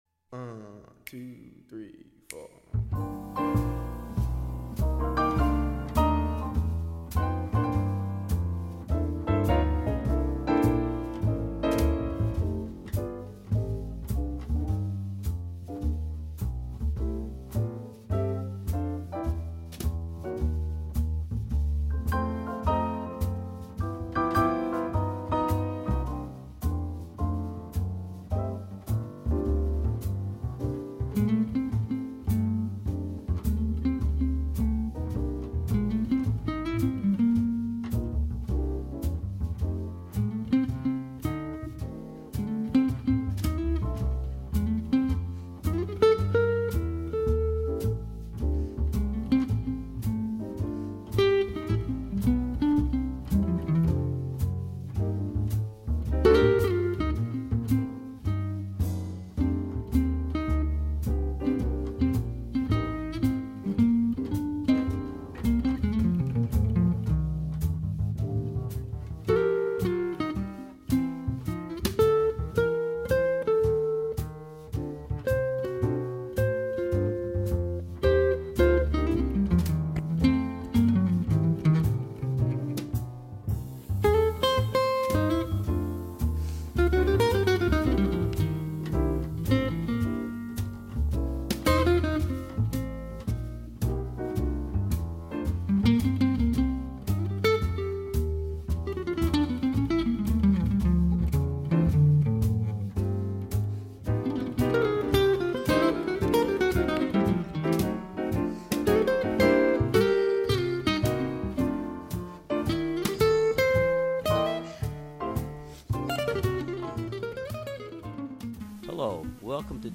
Swinging into Advent; ; 12/6/15; Set 1 Subtitle: cool jazz set Program Type: Unspecified Speakers: Version: 1 Version Description: Version Length: 1 a.m. Date Recorded: Dec. 6, 2015 1: 1 a.m. - 54MB download